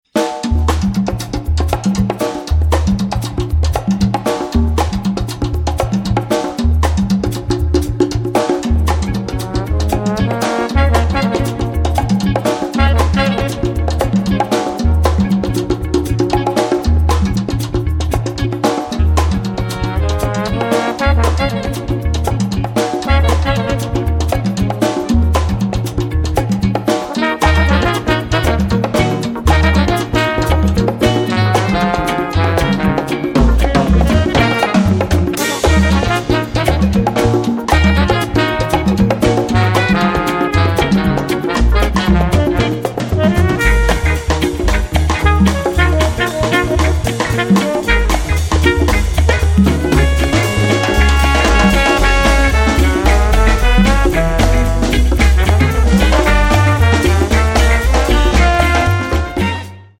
Reggae, Rocksteady, Jamaican Jazz mit Dancehall Kicks